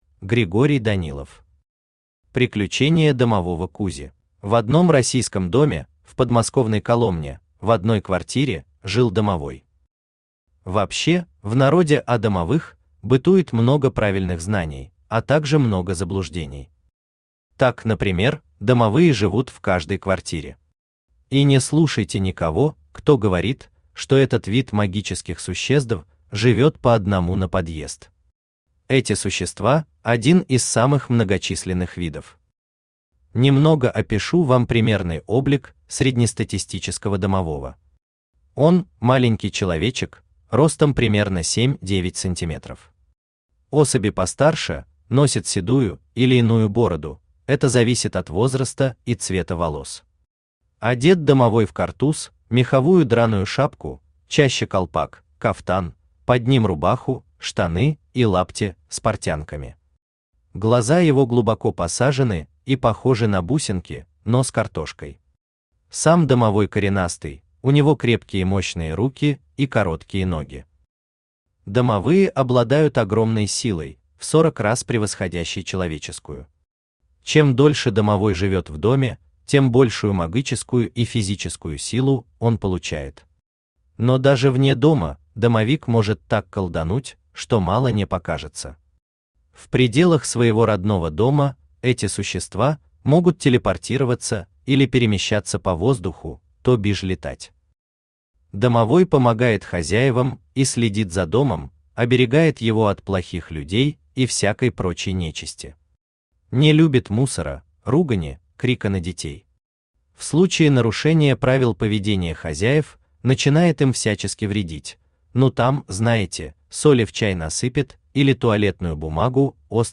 Аудиокнига Приключения домового Кузи | Библиотека аудиокниг
Aудиокнига Приключения домового Кузи Автор Григорий Евгеньевич Данилов Читает аудиокнигу Авточтец ЛитРес.